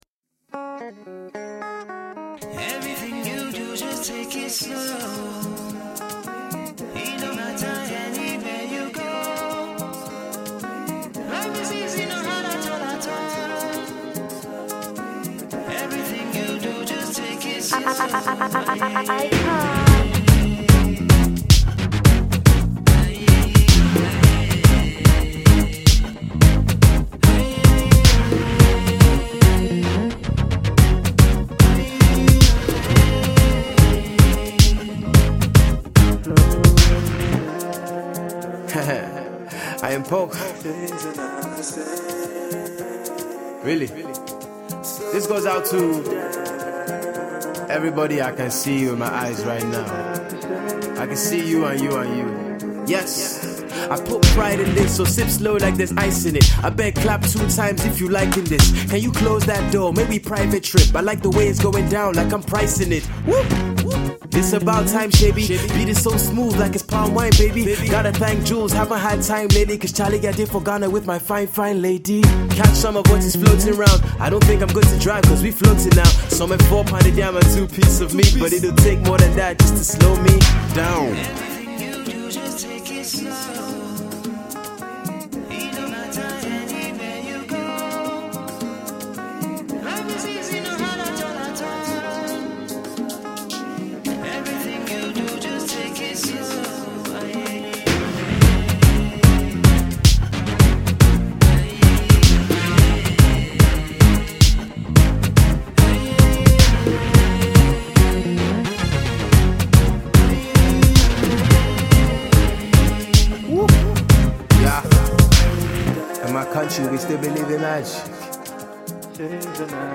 a fusion of highlife and hip-hop